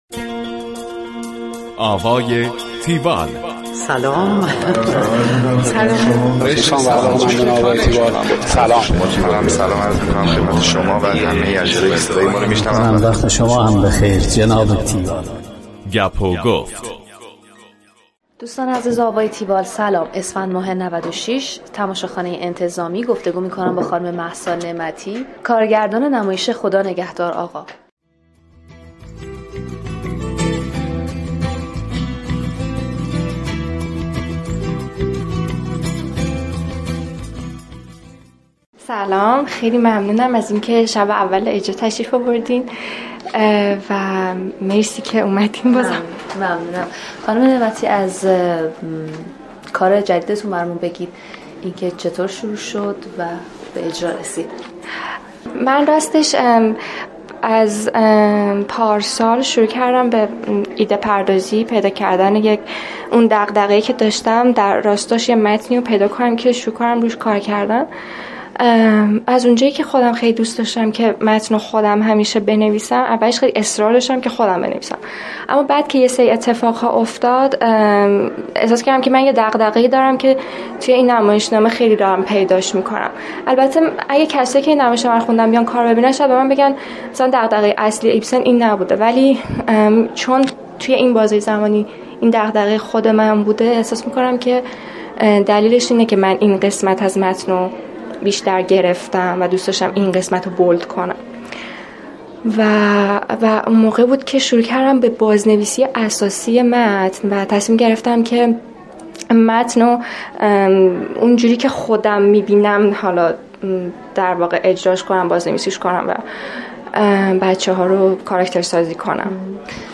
گفتگوی تیوال